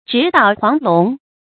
讀音讀法：
直搗黃龍的讀法